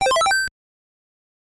Начисление игрового бонуса